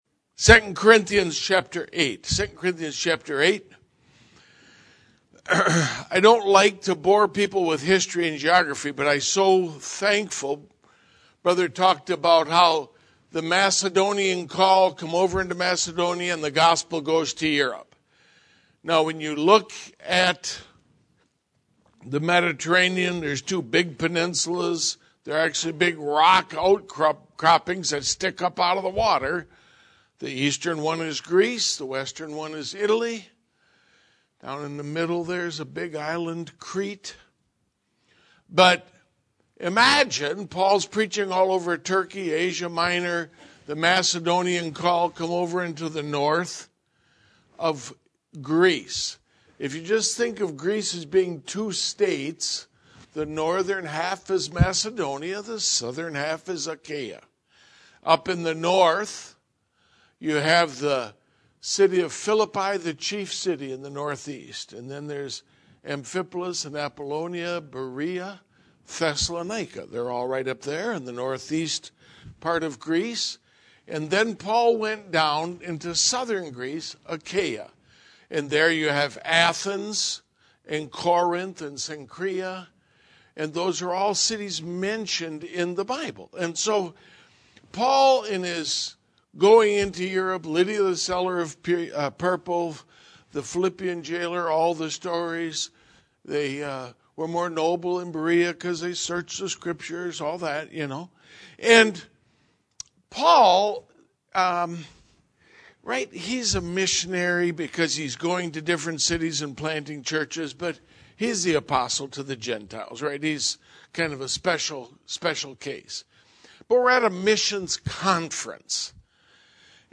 This sermon is an exposition of 2 Cor 8:1-15, that gives us seven good reasons why we should give.
2022 Missions Conference